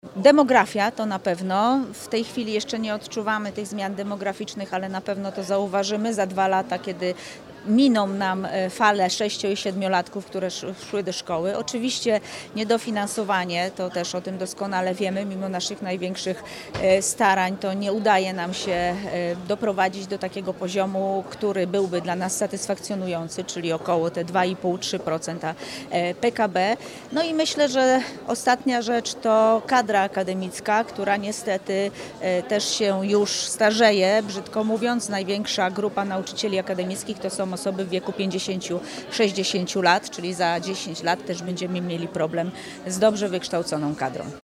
Jak podkreśla prof. Maria Mrówczyńska, problemy systemowe są znane od lat, jednak ich konsekwencje dopiero w nadchodzących latach będą coraz bardziej odczuwalne.